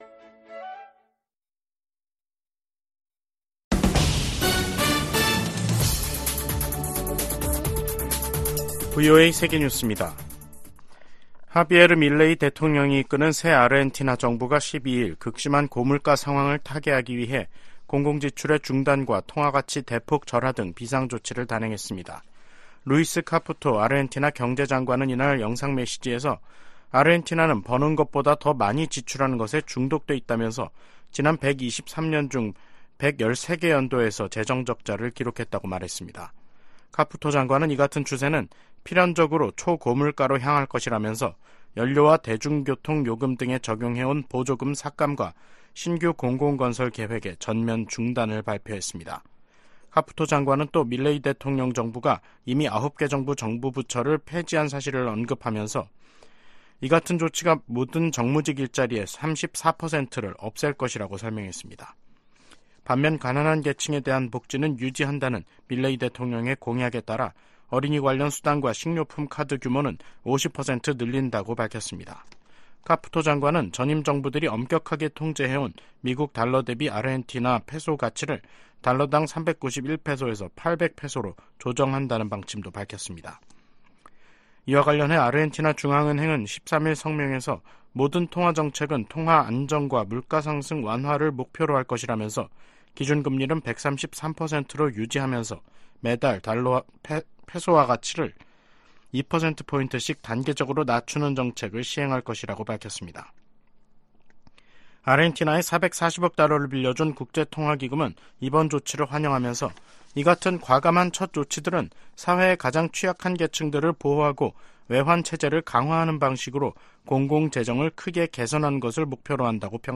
VOA 한국어 간판 뉴스 프로그램 '뉴스 투데이', 2023년 12월 13일 3부 방송입니다. 미국 재무부가 러시아 기업에 반도체 기술을 제공한 한국인을 제재했습니다. 미국이 북한 위협에 대응해 구축한 미사일 방어체계를 이용해 중거리탄도미사일을 공중 요격하는 시험에 성공했습니다. 미 국방부는 B-21전폭기 등 신형 핵전력이 선제타격 목적이라는 북한의 주장을 일축했습니다.